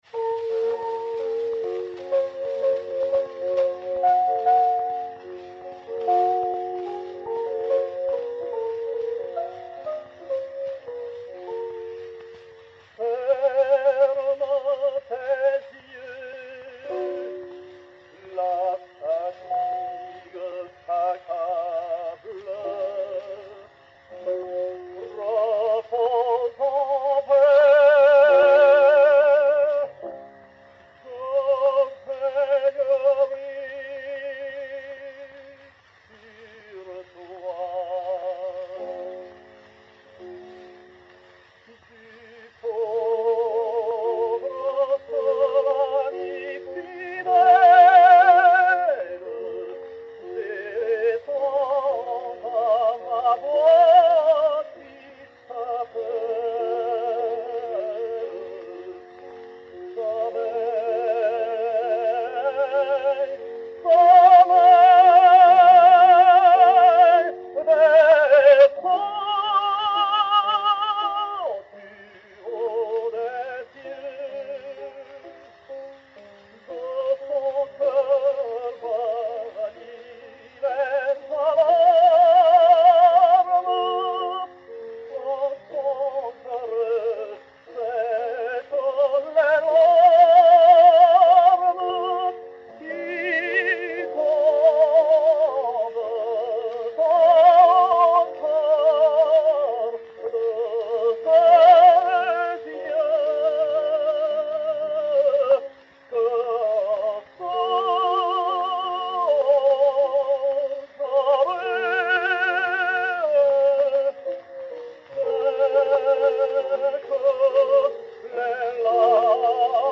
ténor français
On a souvent fait l'éloge de la voix à la fois puissante et charmeresse de Cossira.
Emile Cossira et Piano
Disque pour Gramophone 3-32142, matrice 3007F, enr. Paris 1904